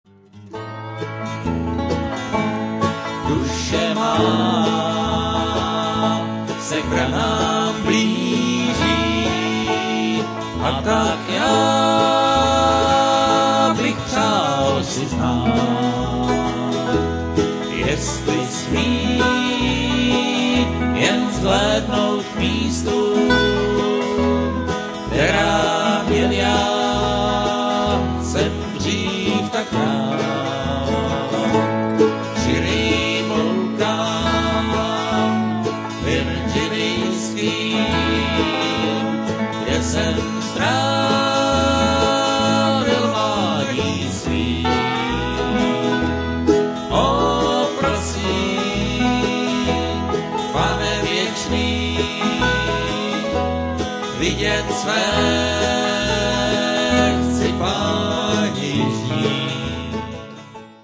banjo
dobro
mandolin